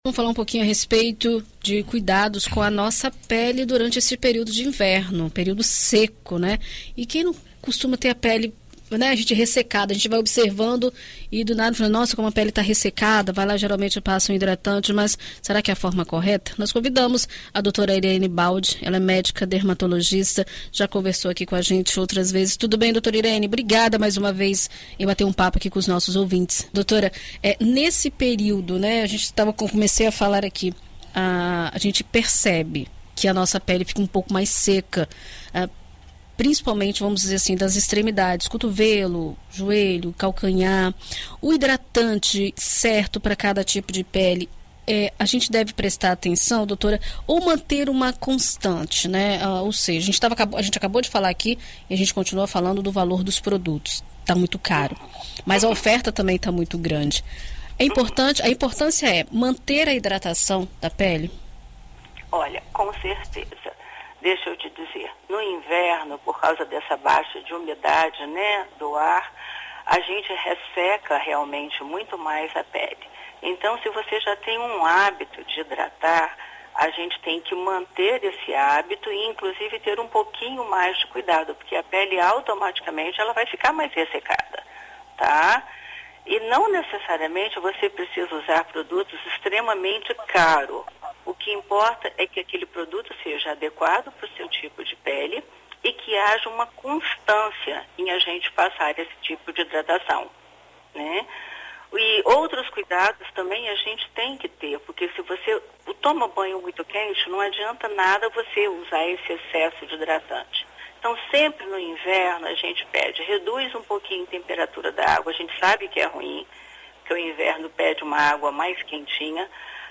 ENT-CUIDADOS-PELE.mp3